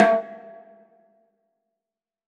WBONGO LW.wav